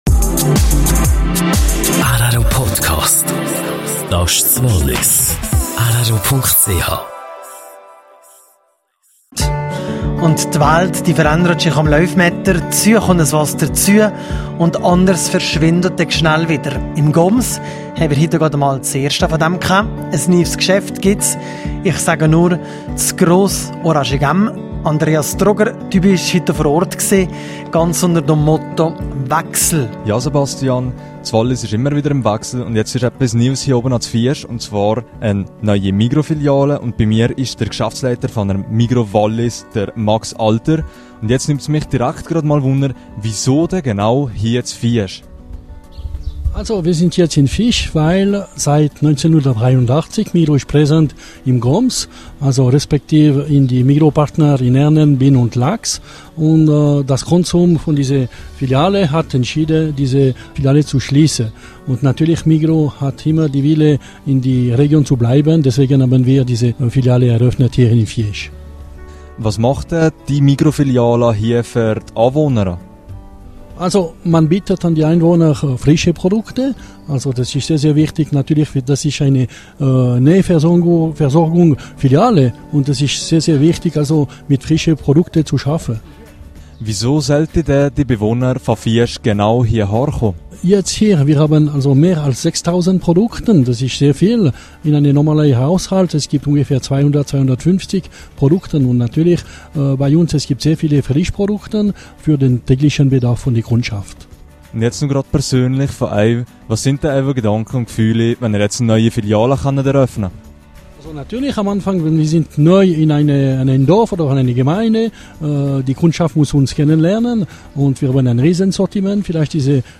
Migros-Filiale in Fiesch: Interview